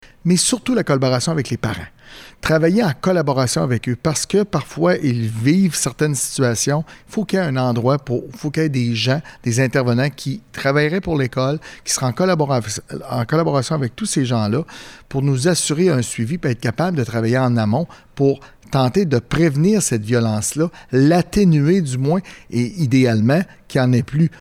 Lors de la conférence de presse de jeudi à Nicolet qui était organisée par la Fédération du personnel de soutien scolaire (FPSS-CSQ) lors de sa tournée des régions, il a été dit que 245 actes ont été dénoncés.